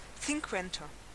Ääntäminen
Ääntäminen France (Paris): IPA: [sɛ̃.kɒ̃t] Tuntematon aksentti: IPA: /sɛ̃.kɑ̃t/ Haettu sana löytyi näillä lähdekielillä: ranska Käännös Ääninäyte Substantiivit 1. cincuenta Suku: m .